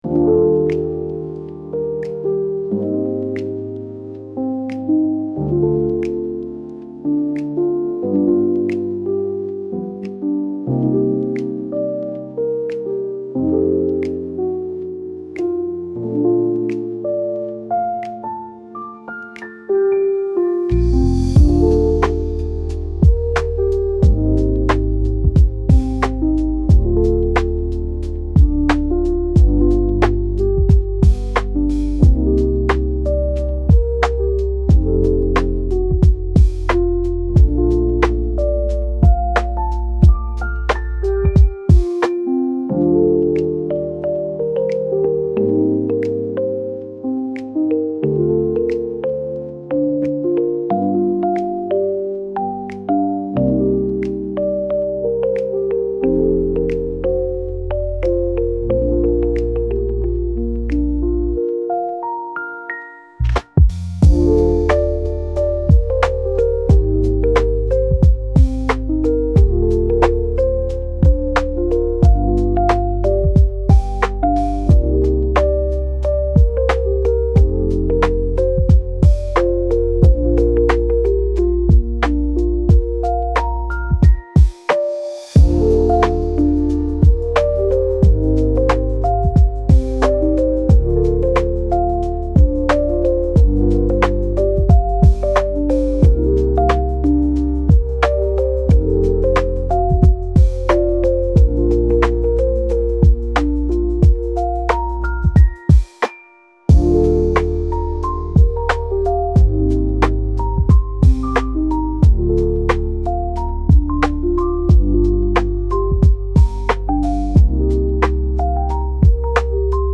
雨音入り癒しのlo-fi/cillミュージック